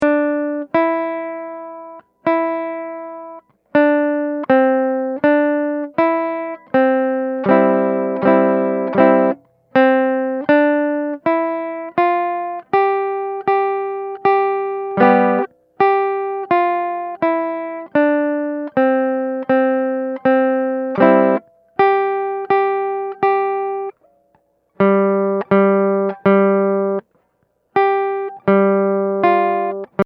Voicing: Guitar